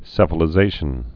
(sĕfə-lĭ-zāshən)